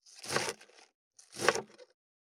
525野菜切る,咀嚼音,ナイフ,調理音,まな板の上,料理,
効果音厨房/台所/レストラン/kitchen食器食材